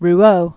(r-ō), Georges 1871-1958.